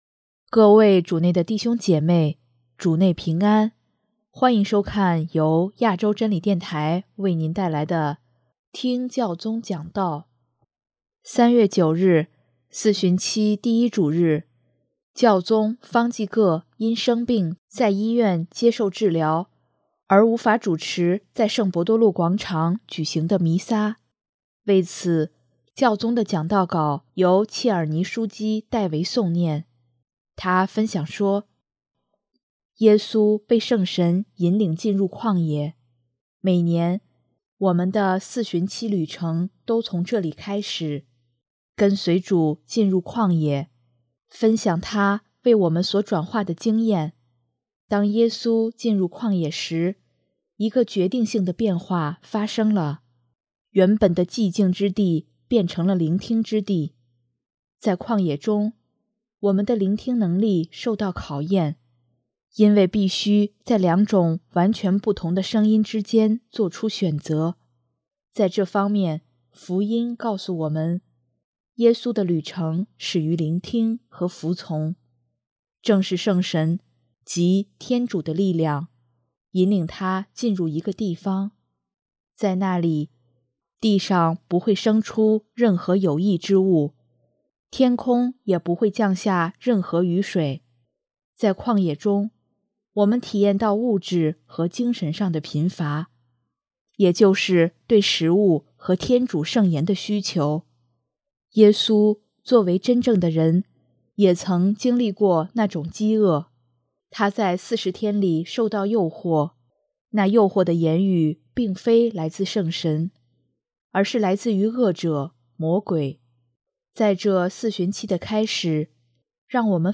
为此，教宗的讲道稿由切尔尼枢机（Cardinal Michael Czerny）代为诵念。